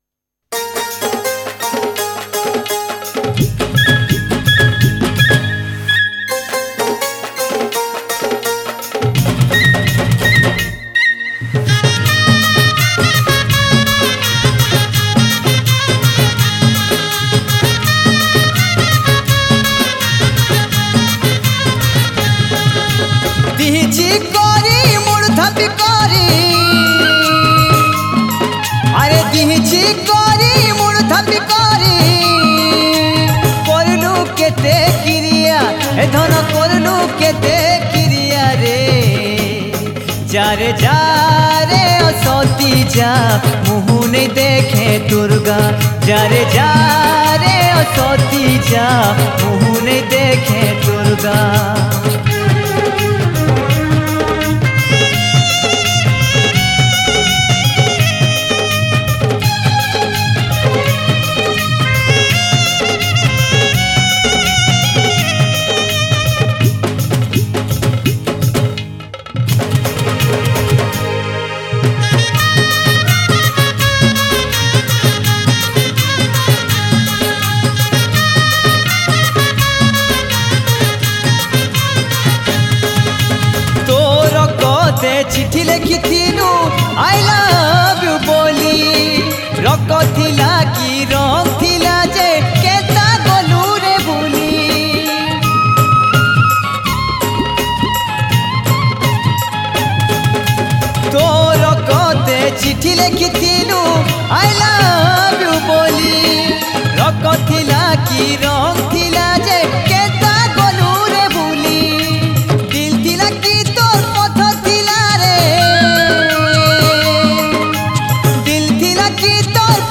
New Sambalpuri Song 2025